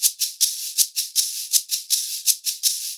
80 SHAK 13.wav